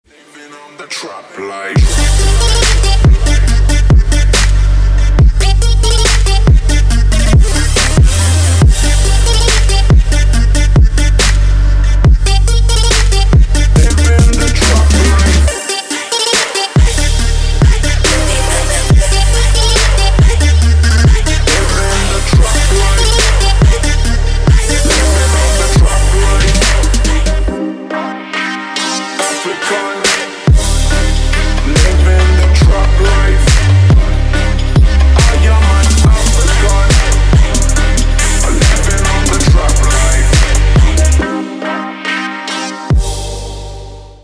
Рингтоны Из Жанра Trap